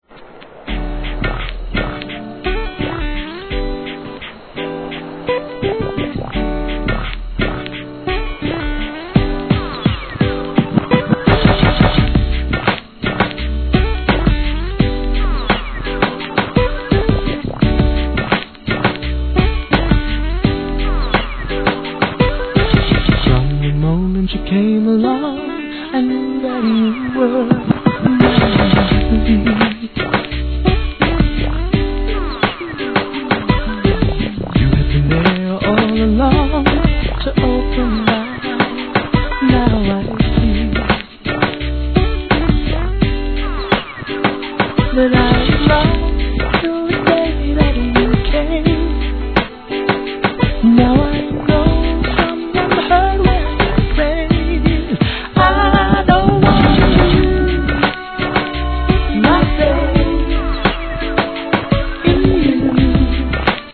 HIP HOP/R&B
アコースティックのメロディーに乗せて歌うSWEETヴォーカルR&B!